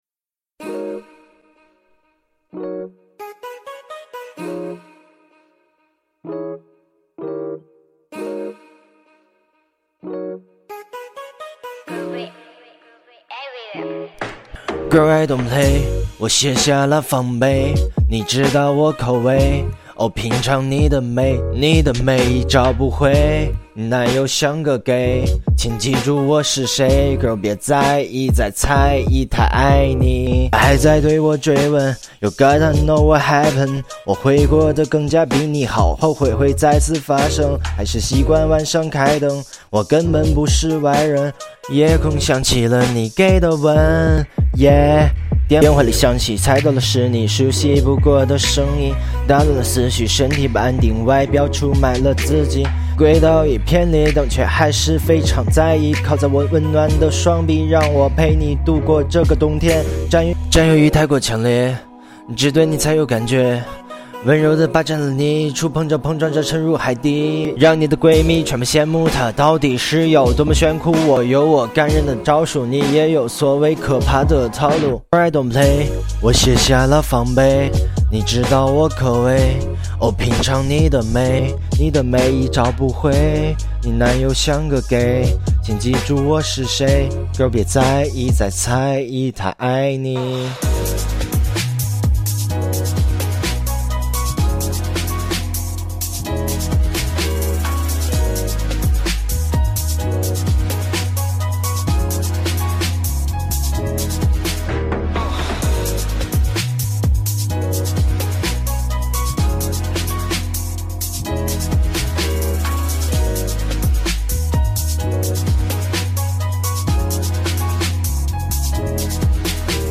这是一首说唱歌曲，节奏和音准偏差严重，压拍错乱。